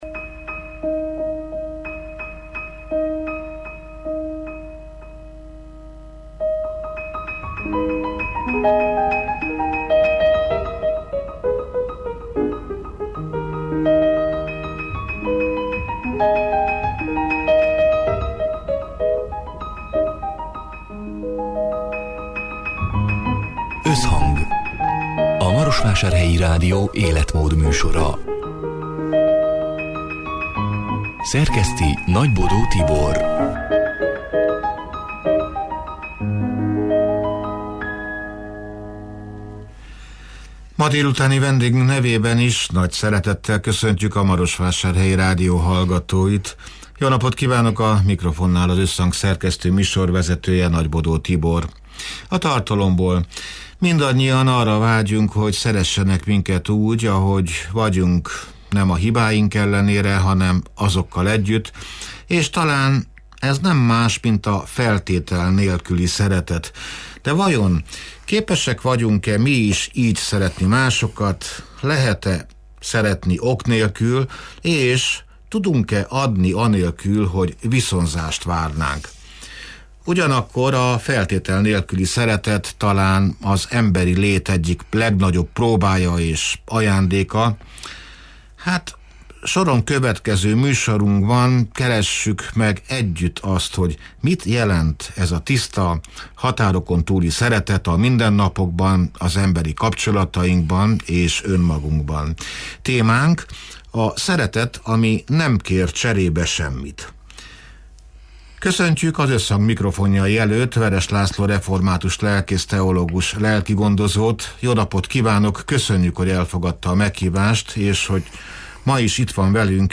(elhangzott: 2025. október 29-én, szerdán délután hat órától élőben)